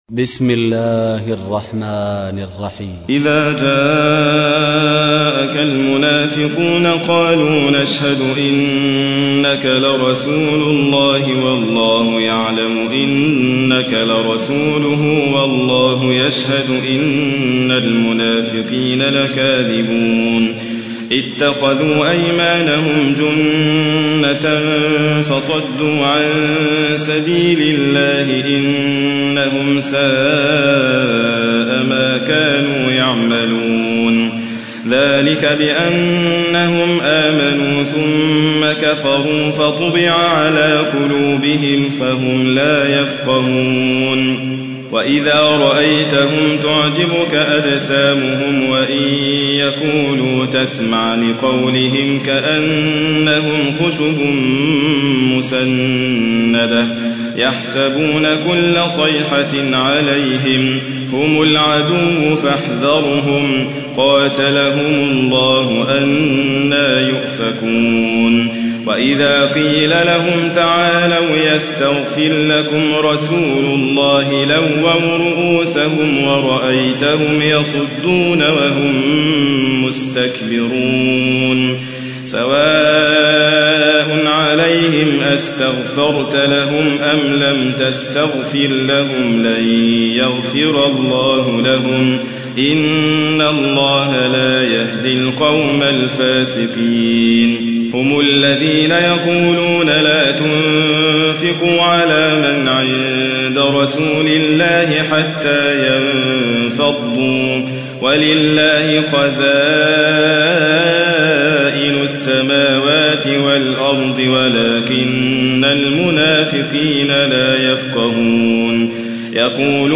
تحميل : 63. سورة المنافقون / القارئ حاتم فريد الواعر / القرآن الكريم / موقع يا حسين